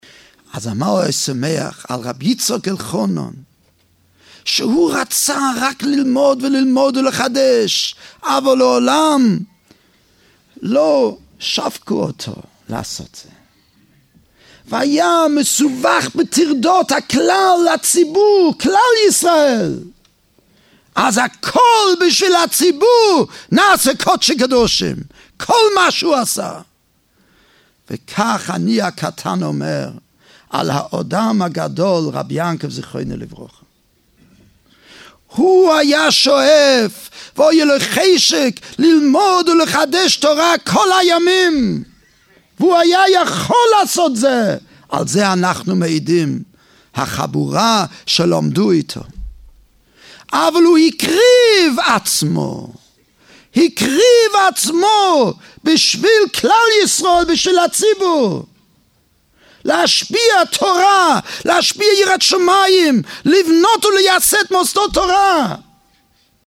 Traduction Simultnée